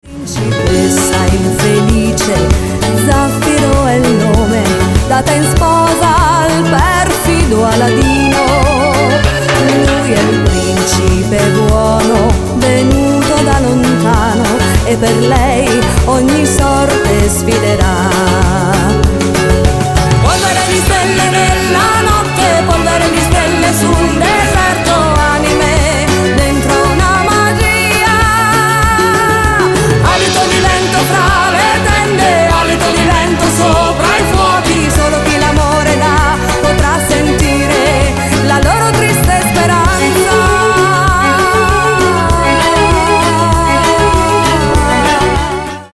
CUMBIA  (3.04)